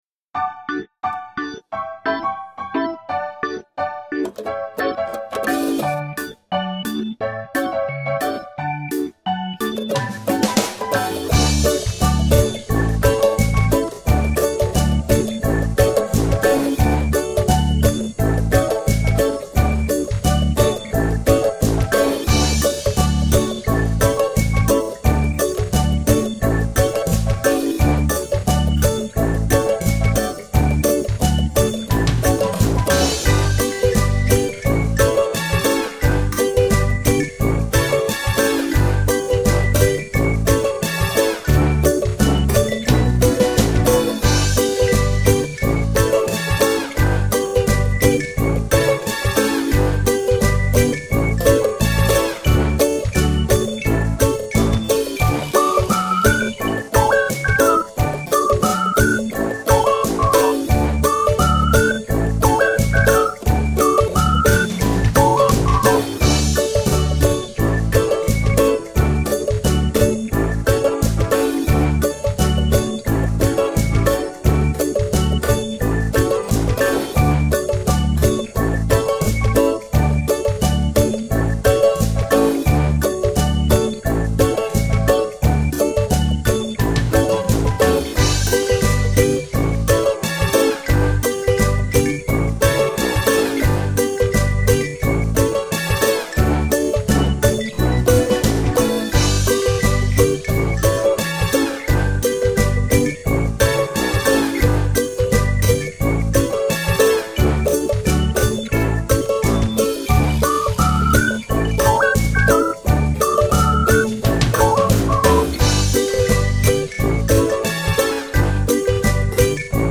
Qui la base musicale (MP3 2.2 MB).